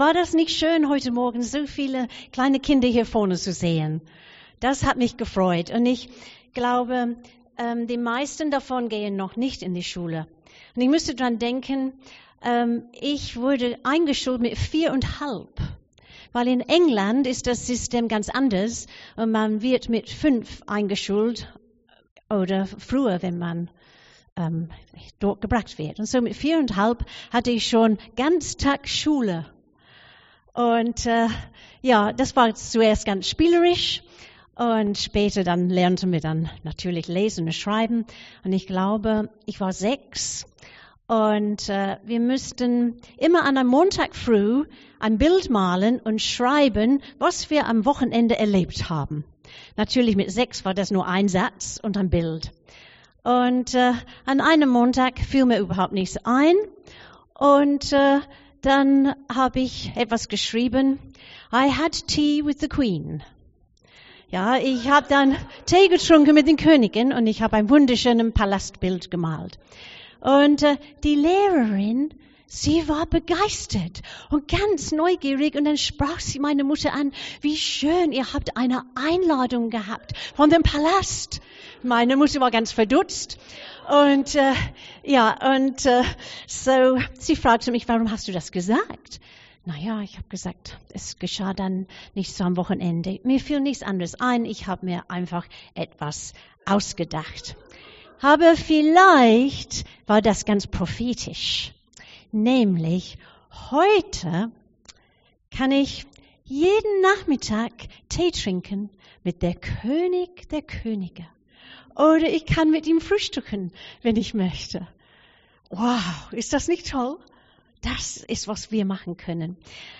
Predigt 14.11.2021